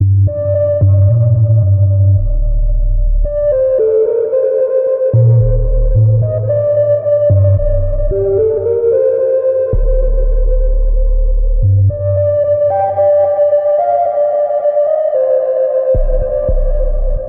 2000年代原版摇滚大鼓
描述：在这里，另一个自我写的鼓2000年的风格，为你支持你自己的摇滚音乐。
标签： 111 bpm Rock Loops Drum Loops 2.91 MB wav Key : Unknown
声道立体声